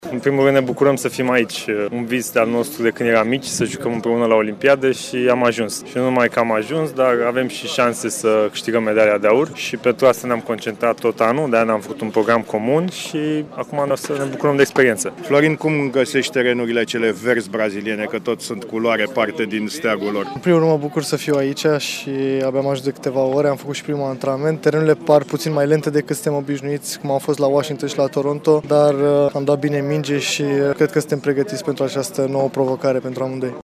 Îi ascultăm pe cei doi jucători.